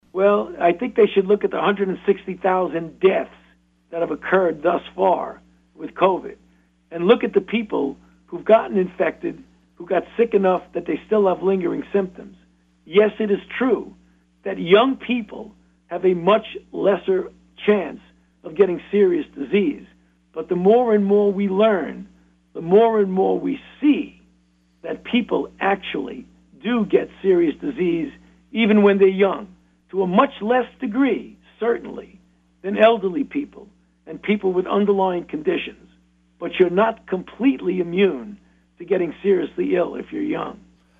One of the most respected infectious disease experts in the world joined KSAL News Friday afternoon for a conversation.